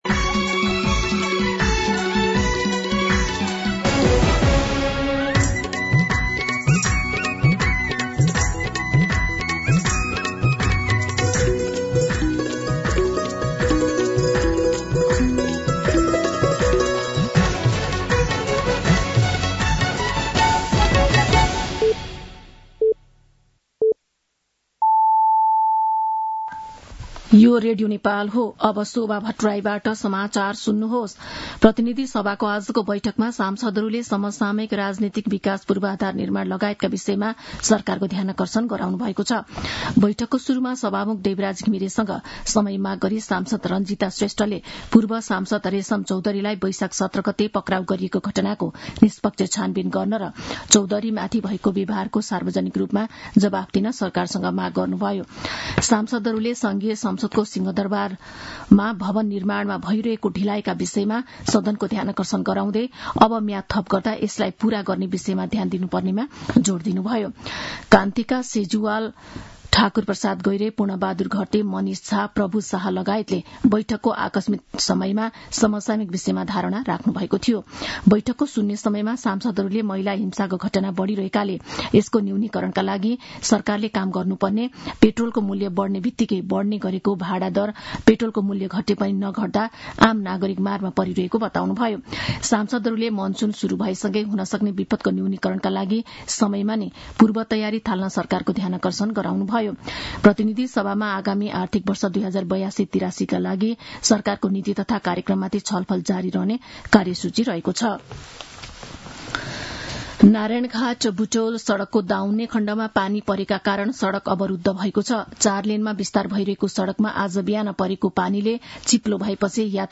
मध्यान्ह १२ बजेको नेपाली समाचार : २३ वैशाख , २०८२